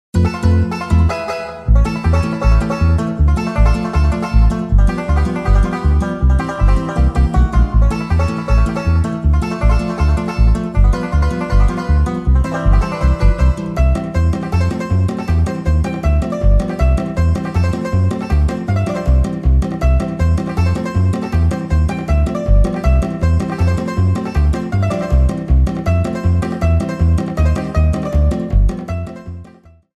Trimmed to 30 seconds and applied fadeout